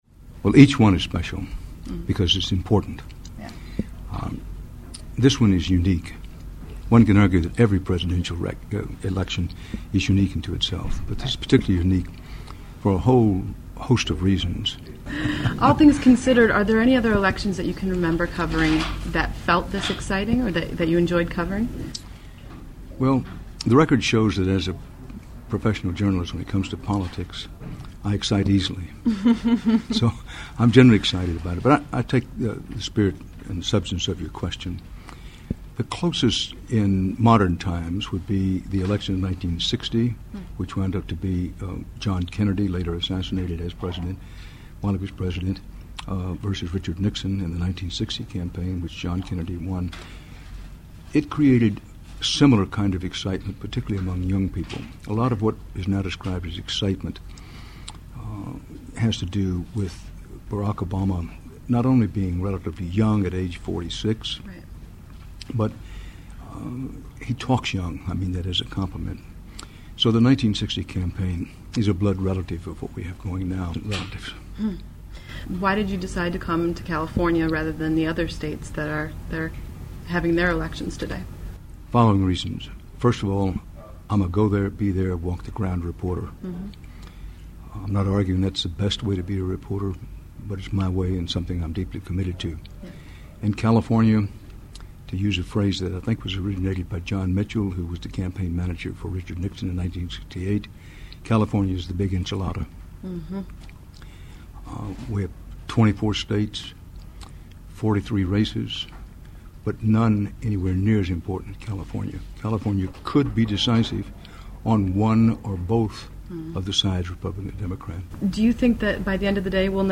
Interview with Dan Rather
HDNet news anchor Dan Rather covered the presidential primary live from USC campus on Super Tuesday.